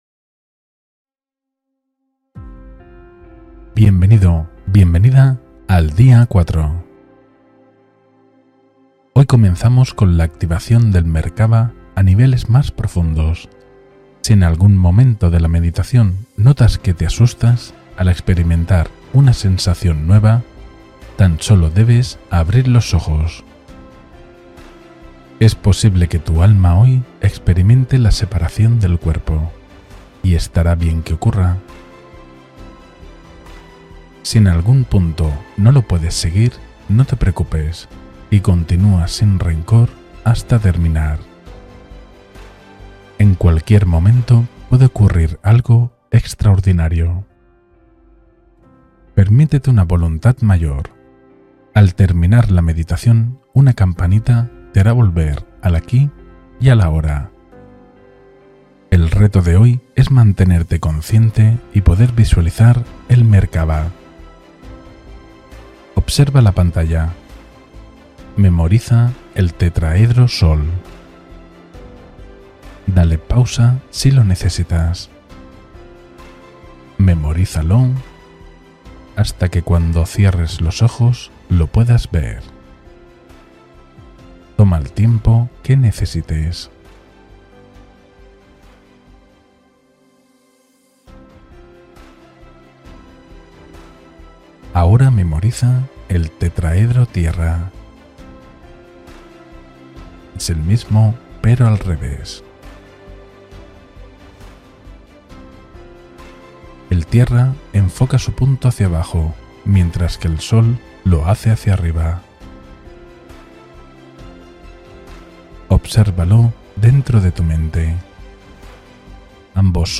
Movimiento Interno del Alma: Meditación Guiada de Tránsito Consciente